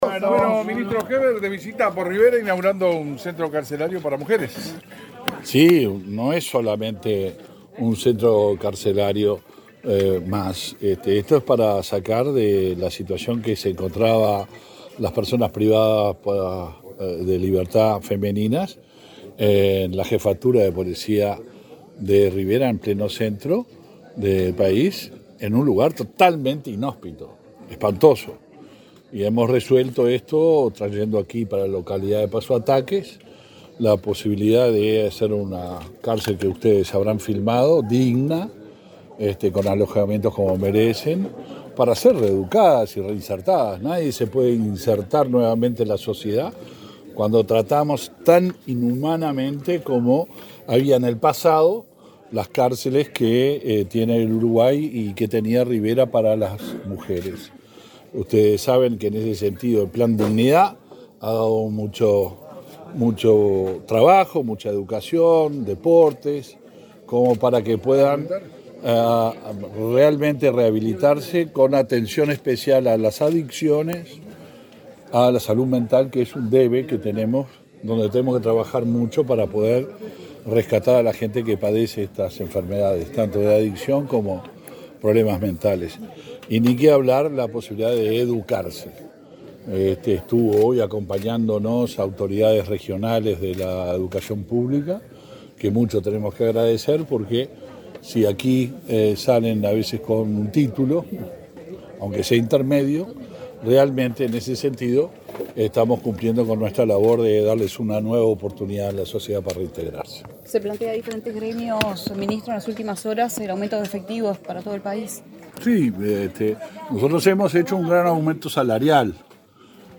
Declaraciones del ministro del Interior, Luis Alberto Heber
Después del evento, Heber dialogó con la prensa.